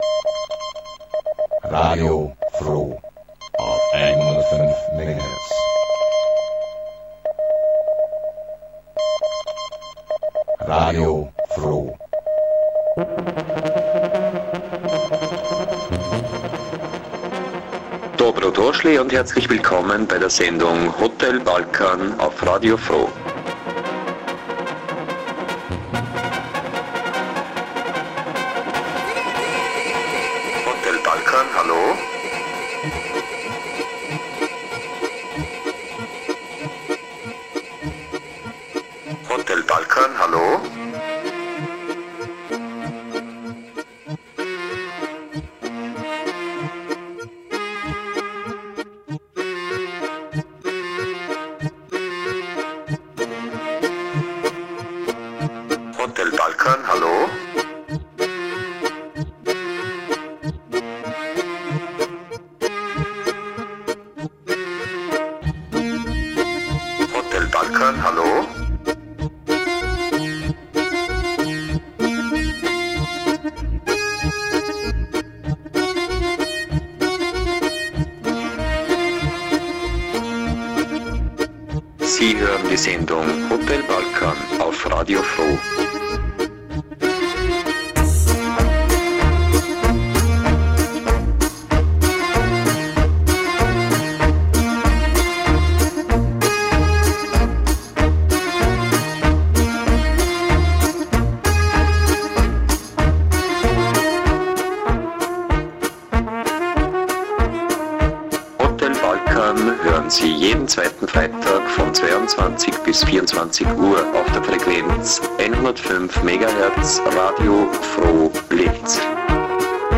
the wicked intercultural radio show